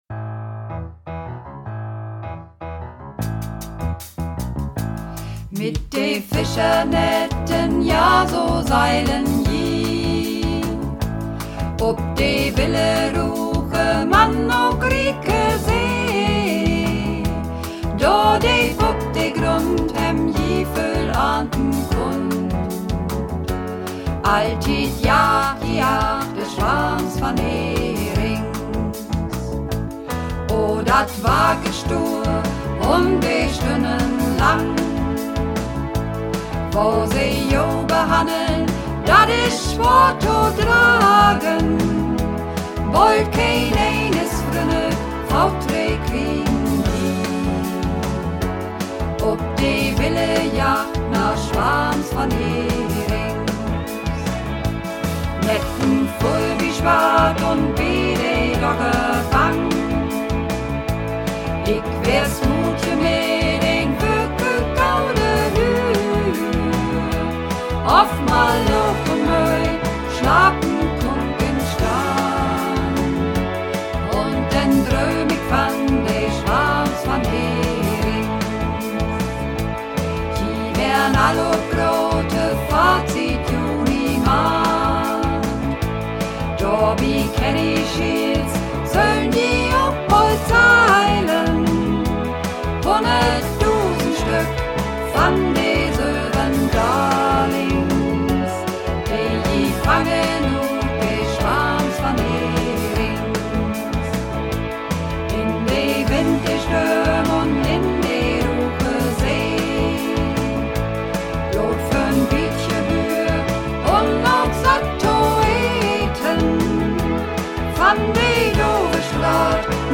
Übungsaufnahmen - Swarms van Herings
Runterladen (Mit rechter Maustaste anklicken, Menübefehl auswählen)   Swarms van Herings Mehrstimmig)